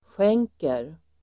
Uttal: [sj'eng:ker]